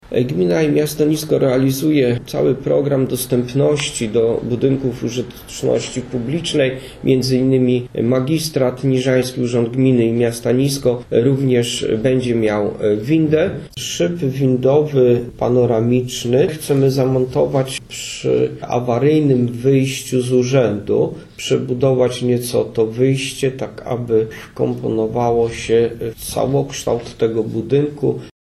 W ramach zadania ma powstać winda zewnętrzna panoramiczna. Mówi burmistrz Niska Waldemar Ślusarczyk: